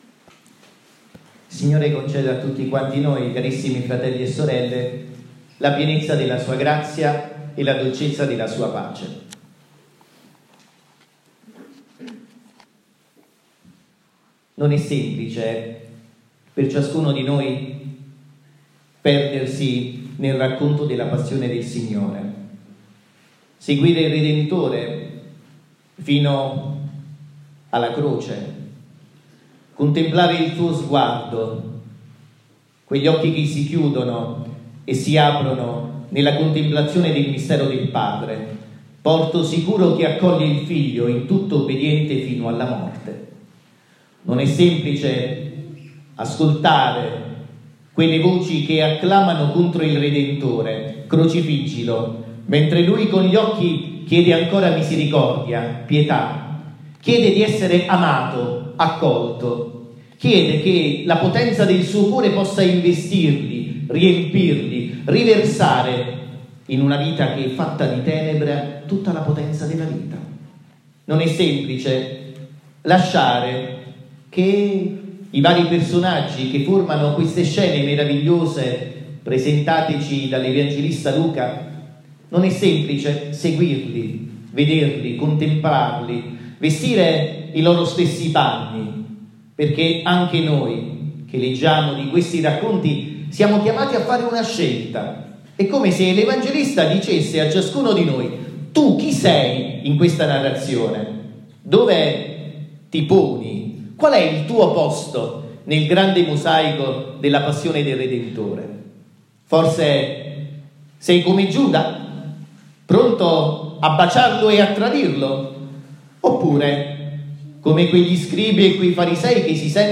omelia-domenica-delle-palme-2022.mp3